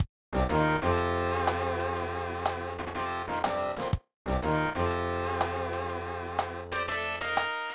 Song
Copyrighted music sample